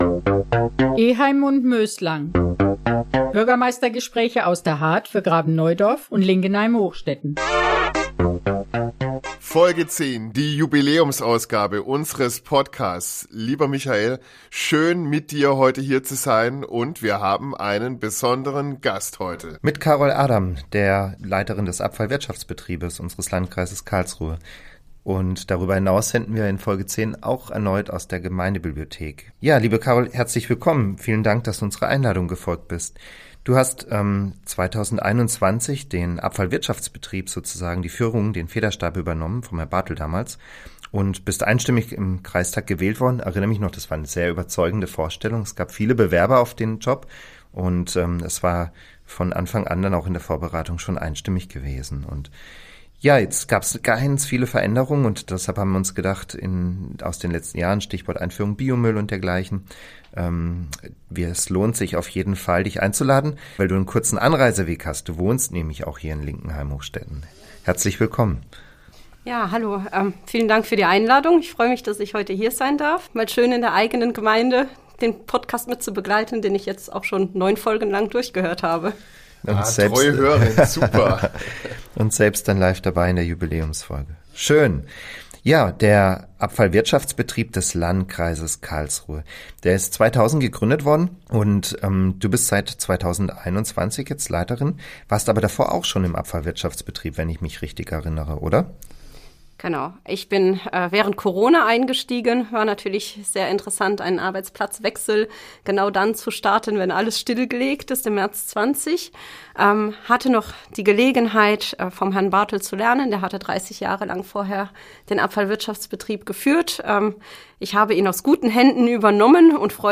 Eheim & Möslang – Der Bürgermeister-Podcast Folge 10 ~ Eheim & Möslang - Bürgermeistergespräche aus der Hardt für Graben-Neudorf und Linkenheim-Hochstetten Podcast